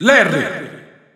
Announcer pronouncing Larry in Italian.
Larry_Italian_Announcer_SSBU.wav